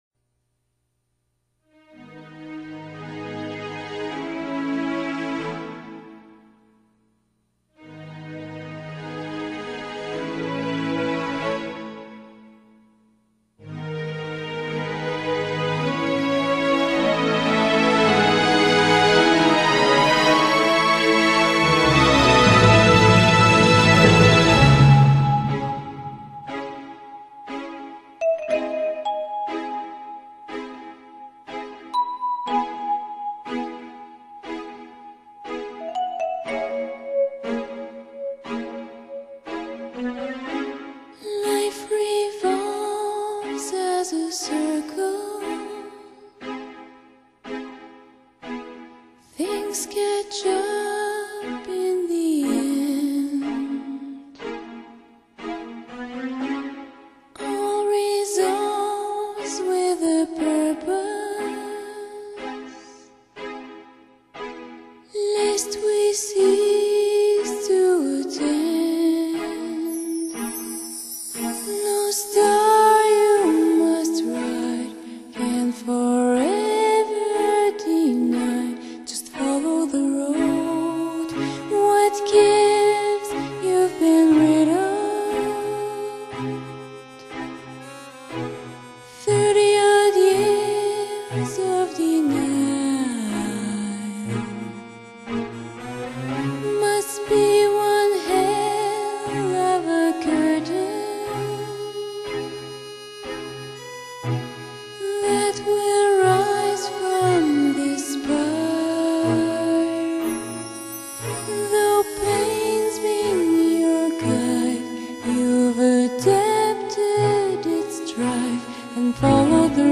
类别：爵士人声
我所看到得最柔柔柔弱的女子,最纤美而天使般声音!
这是天使的吟唱!